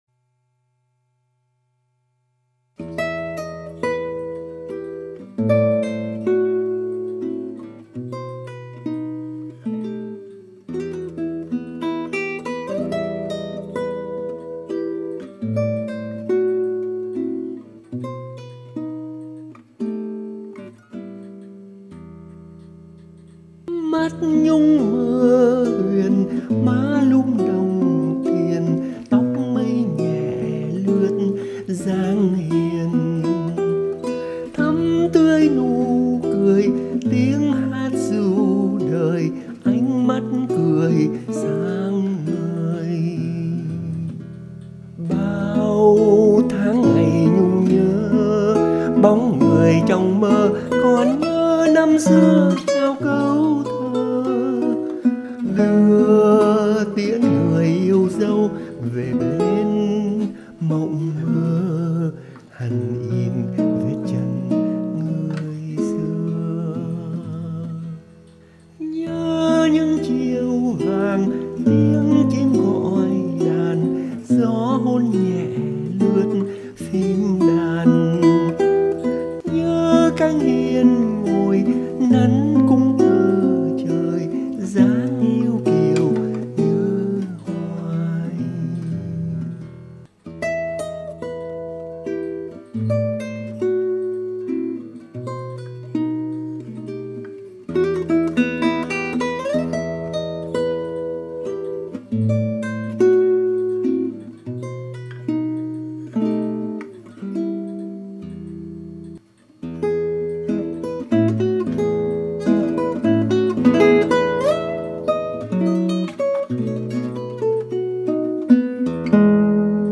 viết cho tây ban cầm